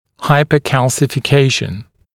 [ˌhaɪpəˌkælsɪfɪ’keɪʃn][ˌхайпэˌкэлсифи’кейшн]гипоминерализация
hypocalcification.mp3